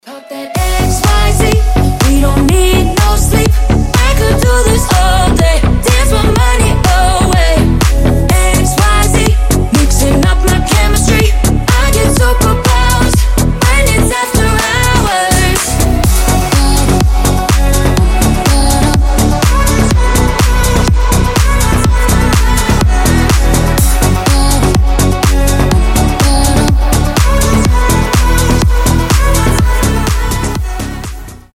EDM
Dance Pop
slap house